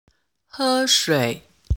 「e」が入った単語を声調別でリストアップしたので、音声を聞いてネイティブの発音をマネしてみて下さい。
「喝水 hē shuǐ」の発音